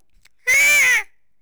eagle_ack1.wav